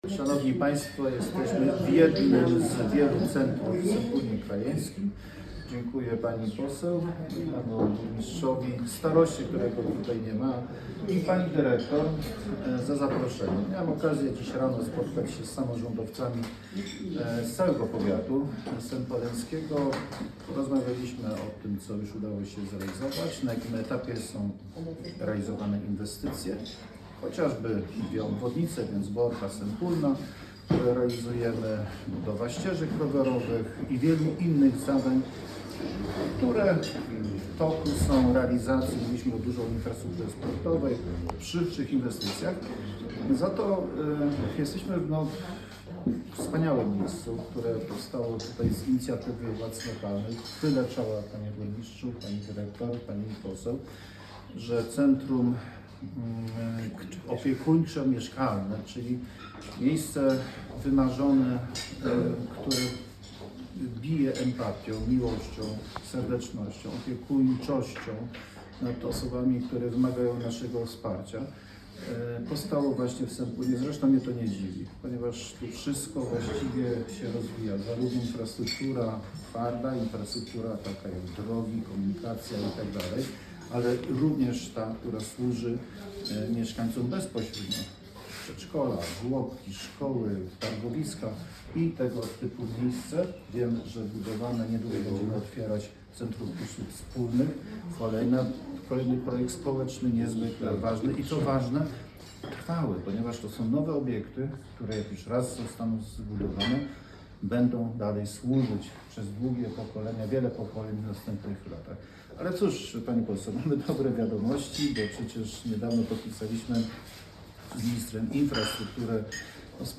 Z wizytą gospodarską w Sępólnie Krajeńskim
Wystąpienie marszałka Piotra Całbeckiego: